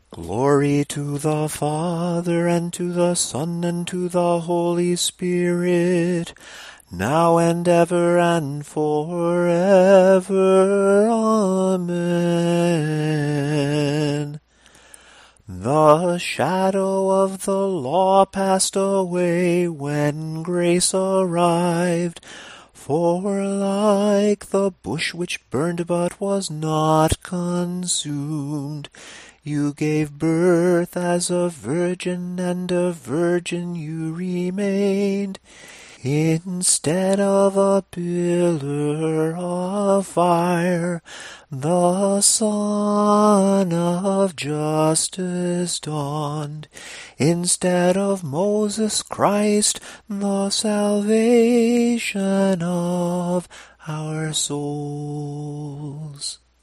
Here is the tone 2 dogmatikon, set to the Tone 2 samohlasen melody. The verse is given, followed by the sticheron. Notice how each phrase flows into the next.
Tone_2_samohlasen_dogmatikon.mp3